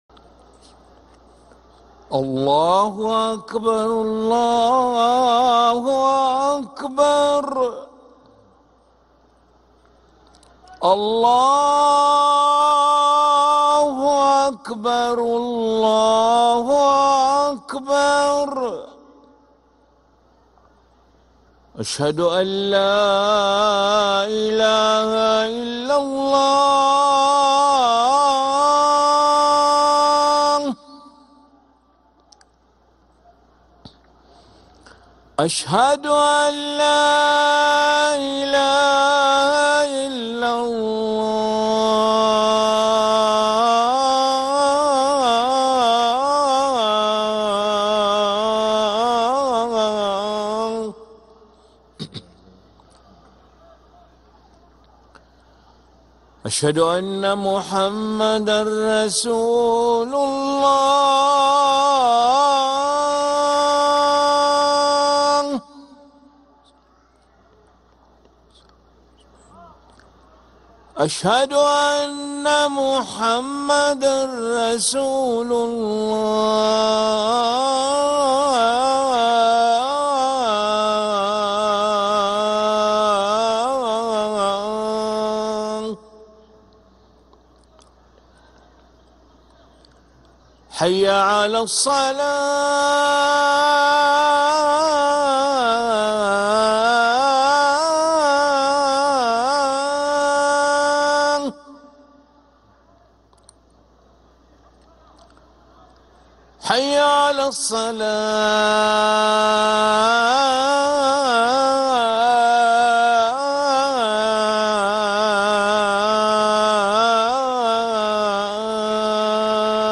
أذان العشاء للمؤذن علي ملا الأحد 5 ربيع الأول 1446هـ > ١٤٤٦ 🕋 > ركن الأذان 🕋 > المزيد - تلاوات الحرمين